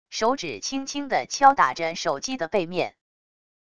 手指轻轻地敲打着手机的背面wav音频